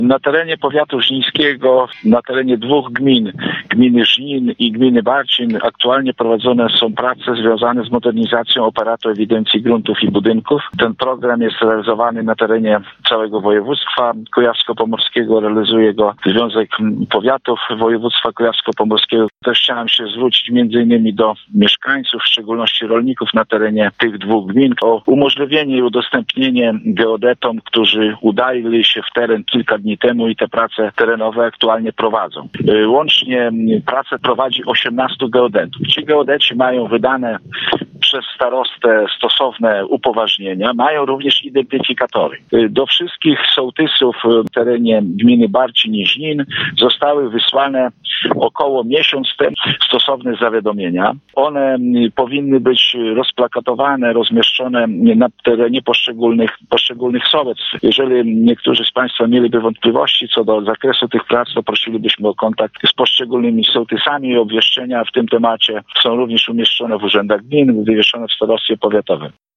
Takich geodetów jest 18-tui wszyscy mają stosowne dokumenty uspokaja Starosta Żniński Zbigniew Jaszczuk